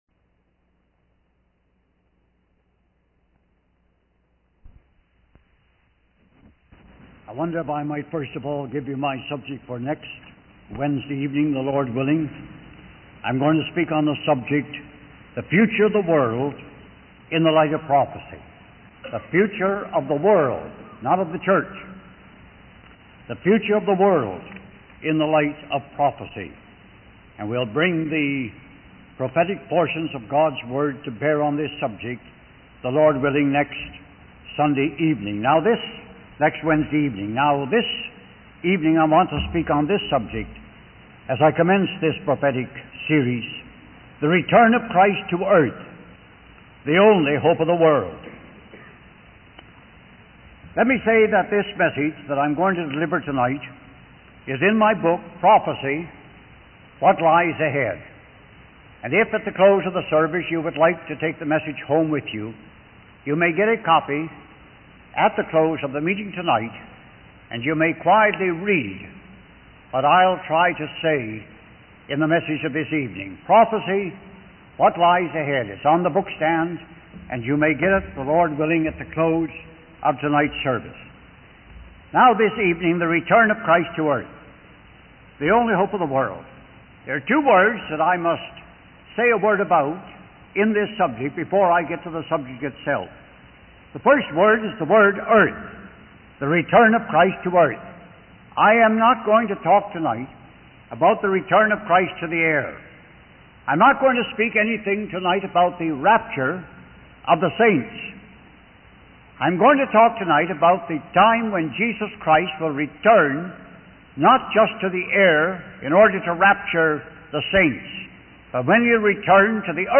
In this sermon, the preacher emphasizes that the world is becoming increasingly evil and corrupt as we approach the end times. He refers to Acts 15:14-18 as the most important prophetic passage in the Bible, which describes the last days of our dispensation. The preacher argues that according to God's word, things are supposed to get worse and worse, not better and better.